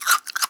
comedy_bite_creature_eating_07.wav